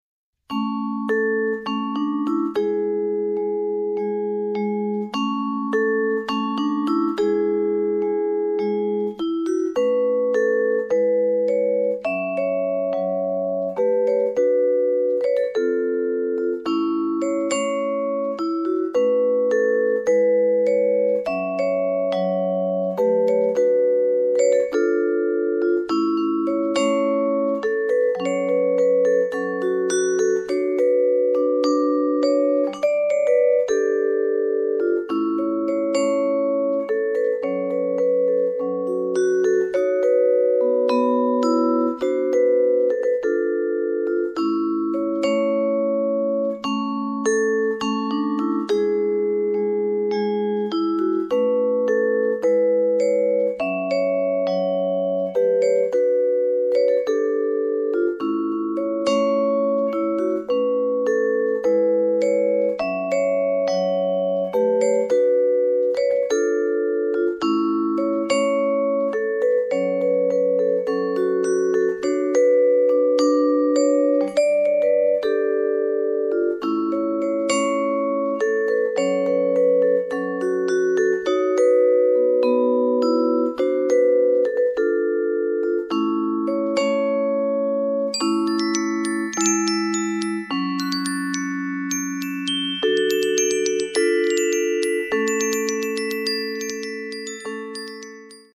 jazzy accompaniments
electric and acoustic guitars
acoustic and electric basses